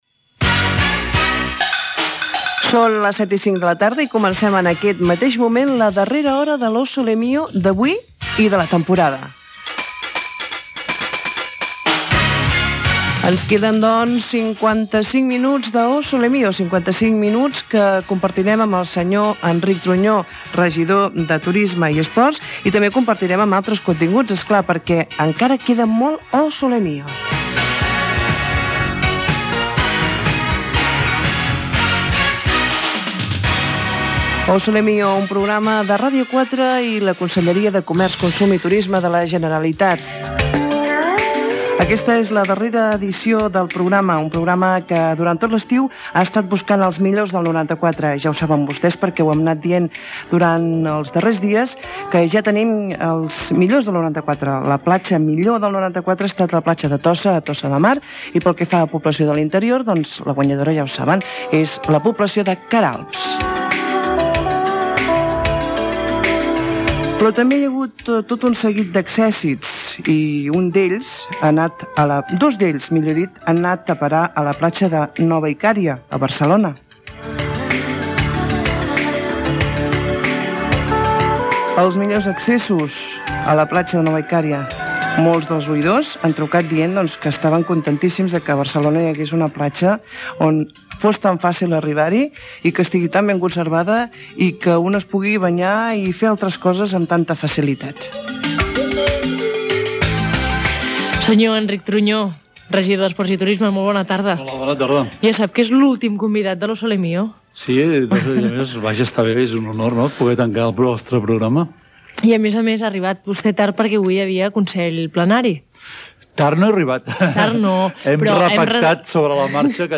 Hora, sumari de continguts, millors platges i localitats d'interior, entrevista al regidor de l'Ajuntament de Barcelona Enric Truñó
Entreteniment
FM